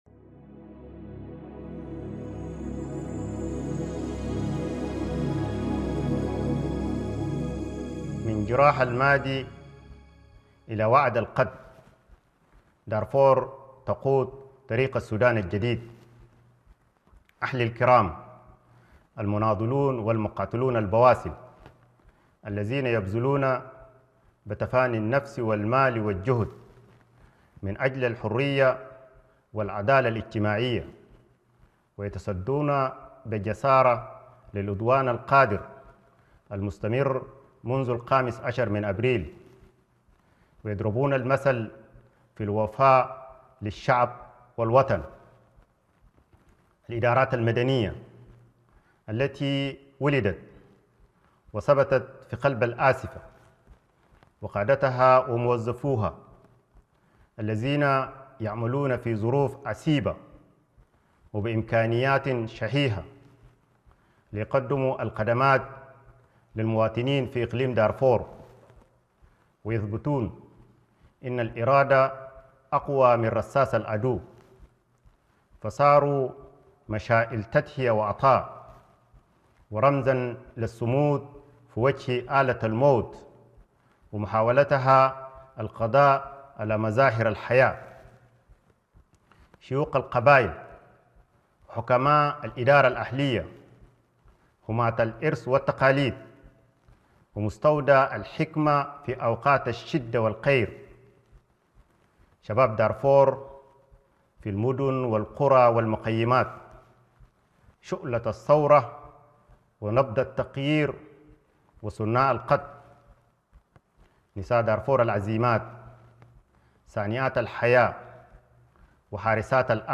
وأضاف حاكم اقليم دارفور وعضو المجلس الرئاسي ، دكتور الهادي ادريس في خطاب للسودانيين ولشعب دارفور، أن التحالف اتفق على حل الحركة الإسلامية وحزب المؤتمر الوطني، وأقر مبادئ فوق دستورية لا يجوز تجاوزها مستقبلاً، مؤكداً أن هذه الرؤية ستكون أساس التفاوض مع مختلف القوى السياسية والاجتماعية، وهي التي ستحدد مدى القرب أو البعد من أي مبادرة أو مشروع للحل الشامل.